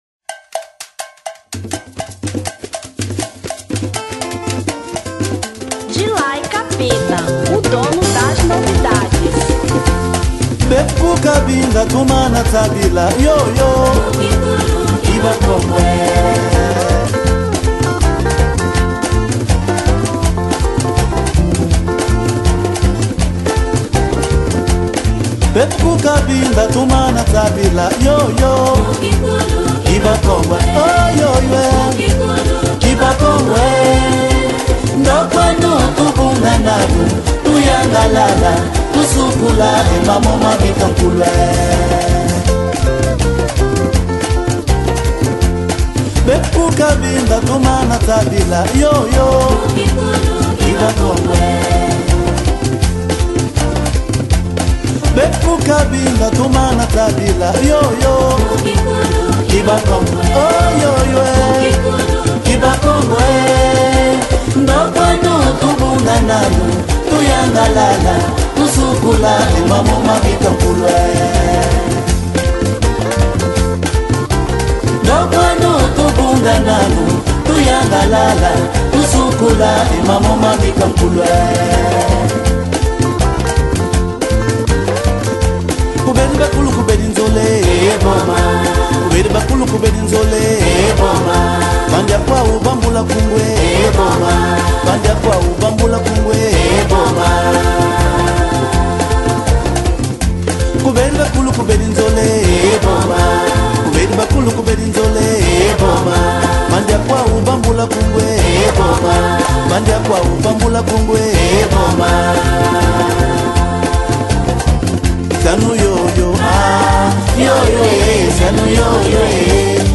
Kizomba 2005